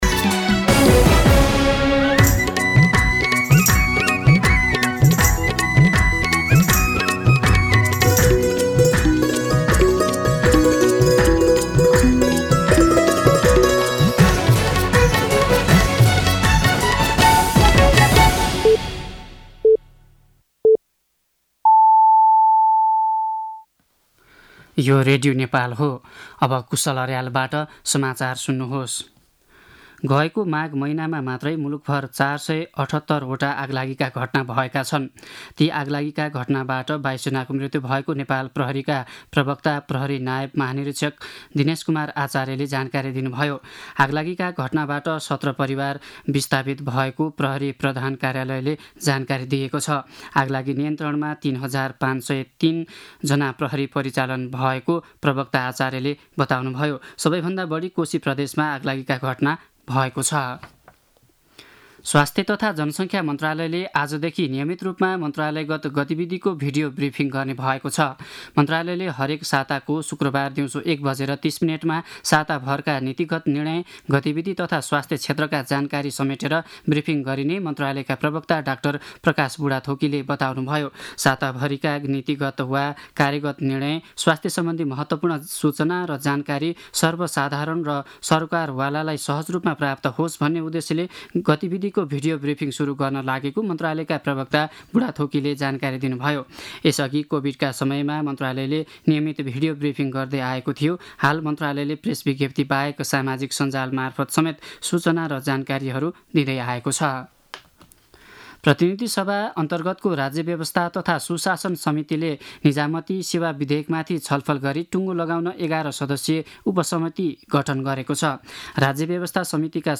दिउँसो ४ बजेको नेपाली समाचार : १० फागुन , २०८१
4-pm-Nepali-News-2.mp3